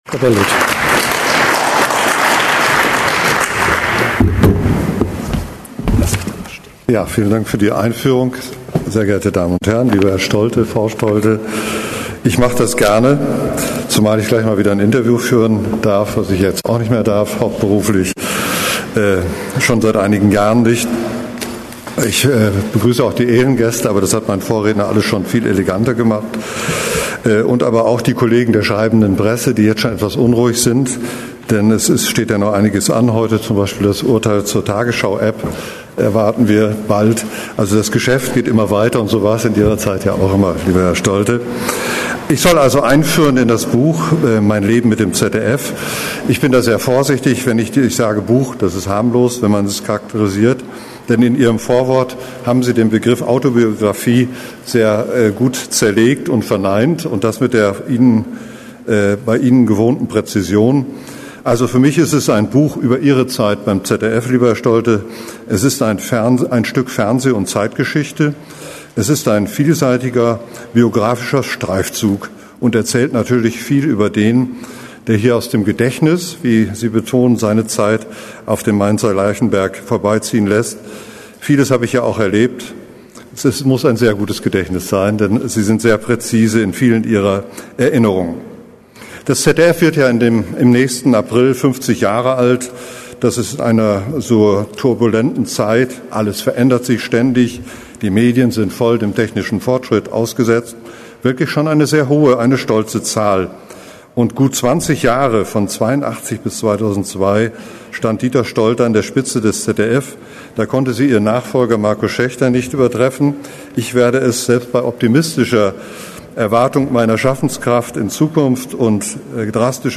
Wer: Thomas Bellut, ZDF-Intendant (seit März 2012)
Was: Rede zur Buchpräsentation
Wo: Berlin, ZDF-Hauptstadtstudio